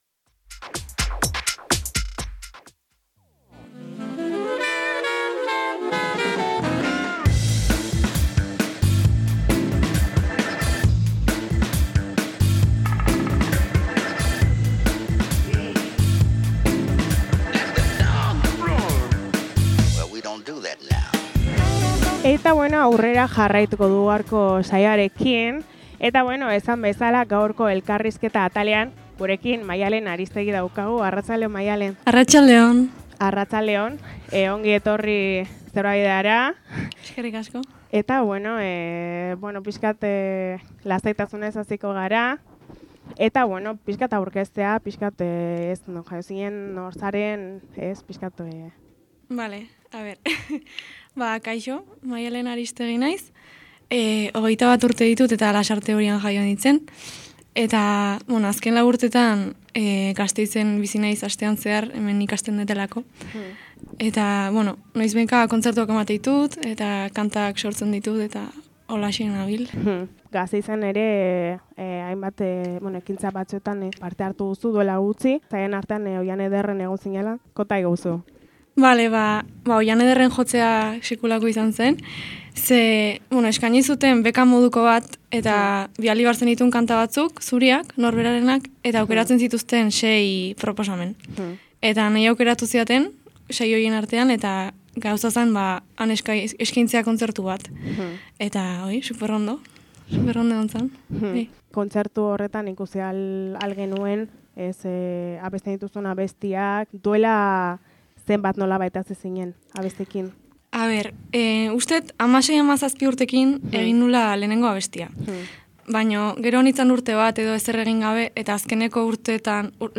Gurekin izateko plazera izan dugu Halabedi irratiko estudioetan eta baita zuzeneko pare bat abestiz gozatzeko aukera ere.